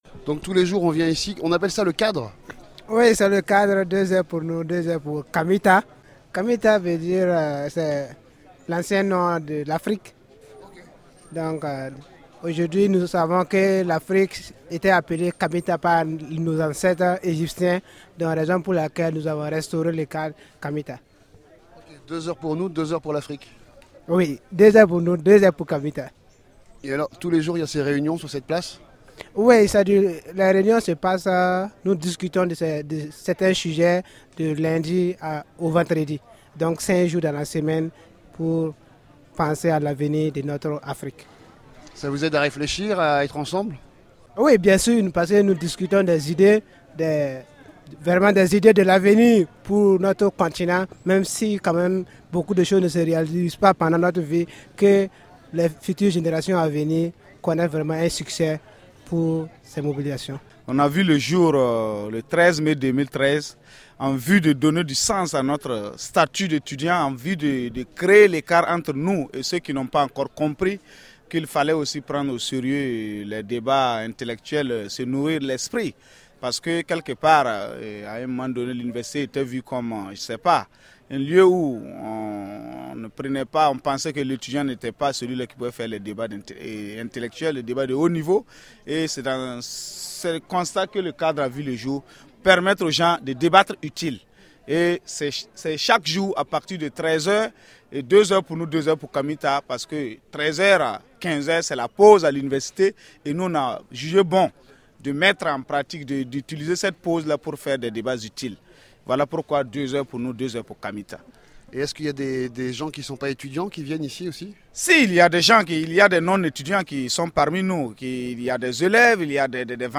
Aux abords de l’université de Ouagadougou a lieu tous les jours « Deux heures pour nous deux heures pour Kamita » un espace de parole autour de sujets locaux et internationaux qui réunit une centaine de personne.